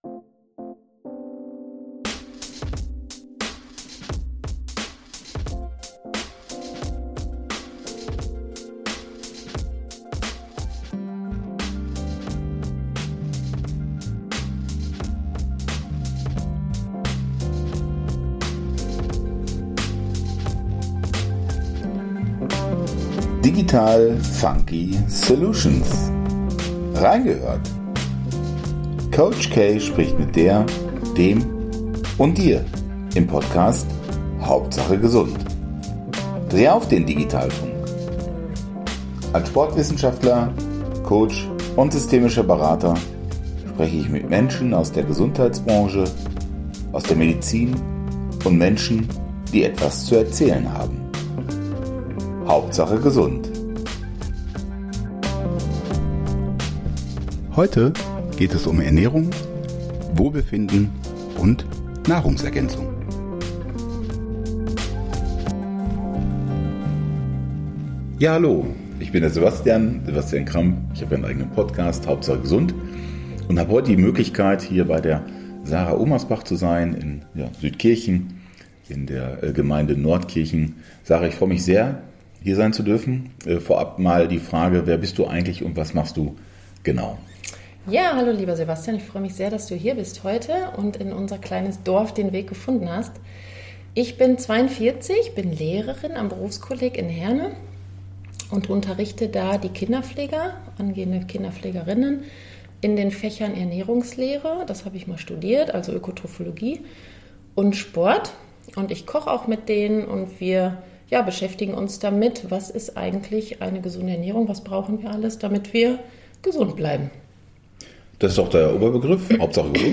Wir trafen uns im Dorf Südkirchen bei einem Tee und Wasser zu dieser spannenden und interessanten Folge.